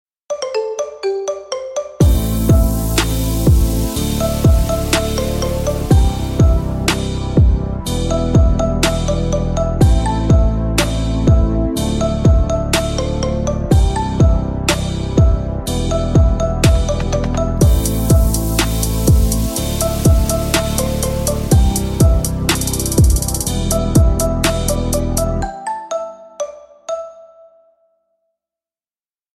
Marimba mix